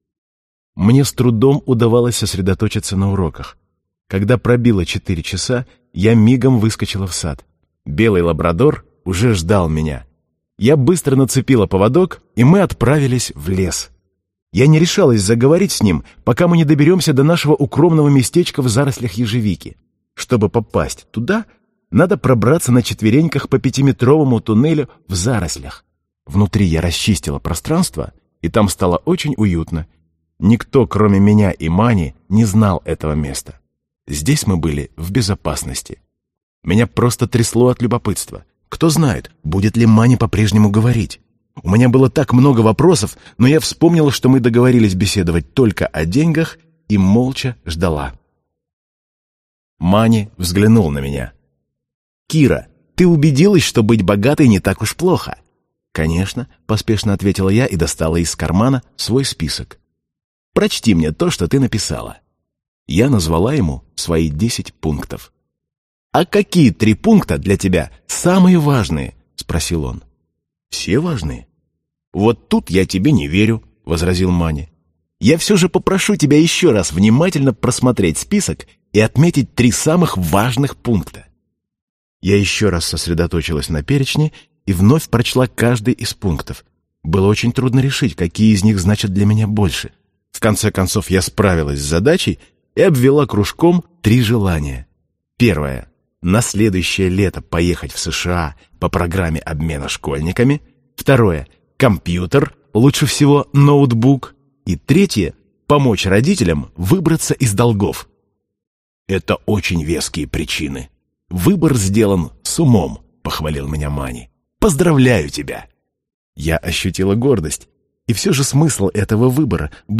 Аудиокнига Мани, или Азбука денег - купить, скачать и слушать онлайн | КнигоПоиск